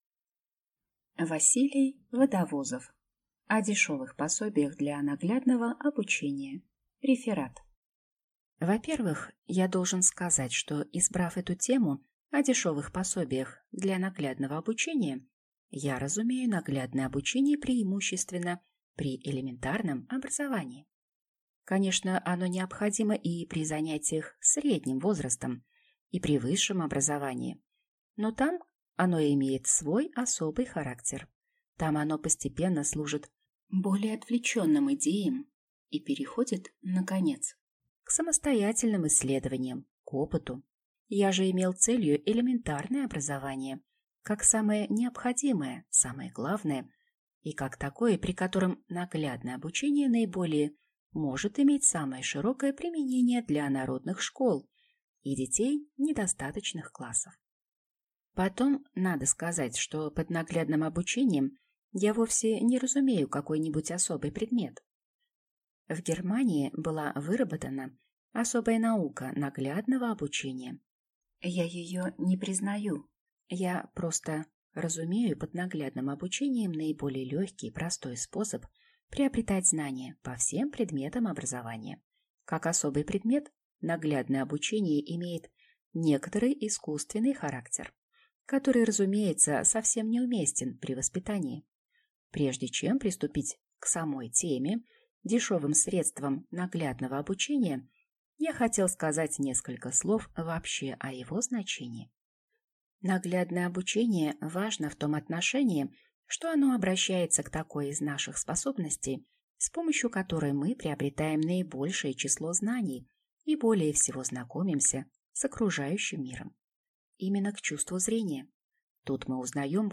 Аудиокнига О дешевых пособиях для наглядного обучения | Библиотека аудиокниг
Прослушать и бесплатно скачать фрагмент аудиокниги